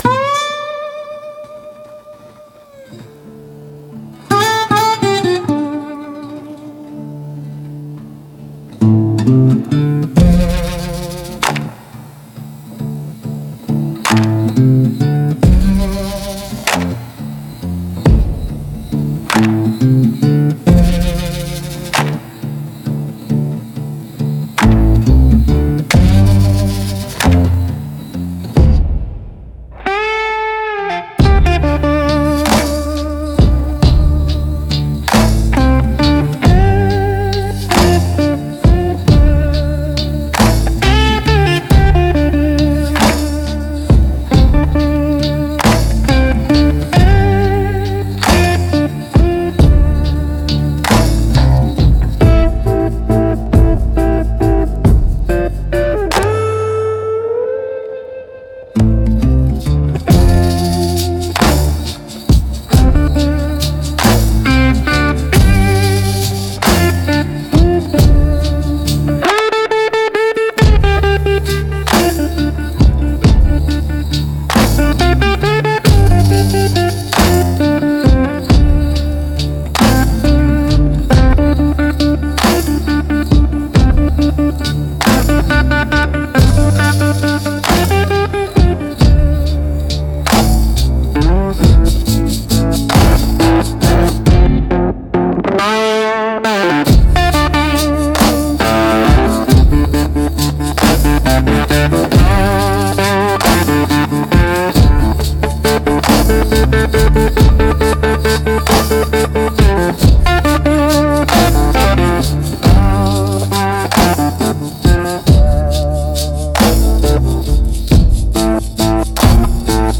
Instrumental - Preacher, Liar, Thief 2.25 - Grimnir Radio